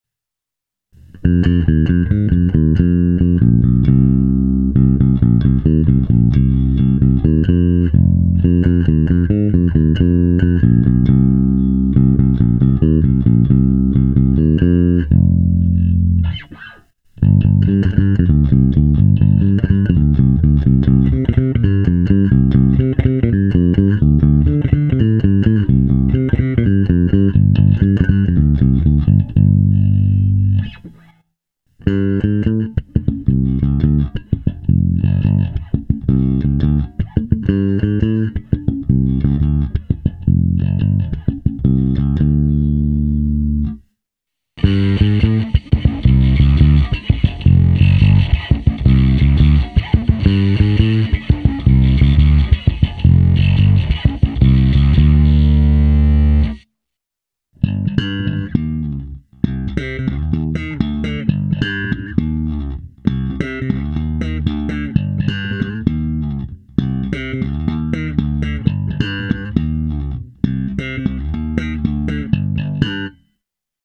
Na rozdíl od zmíněného modelu SB-1000 jsem v tomto případě nemusel moc se zvukem laborovat, okamžitě se mi nejvíce zalíbila poloha číslo 1 filtru s použitím obou snímačů a nakonec jsem skoro úplně stáhnul tónovou clonu. Nahrál jsem s ní další ukázku včetně slapu.